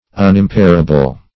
Unimpairable \Un`im*pair"a*ble\, a. That can not be impaired.
unimpairable.mp3